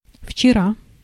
Ääntäminen
Vaihtoehtoiset kirjoitusmuodot (rikkinäinen englanti) yestaday Synonyymit the last day Ääntäminen US : IPA : [ˈjes.tə.deɪ] UK RP : IPA : /ˈjɛstədeɪ/ IPA : /ˈjɛstədɪ/ GenAm: IPA : /ˈjɛstɚdeɪ/ IPA : /ˈjɛstɚdɪ/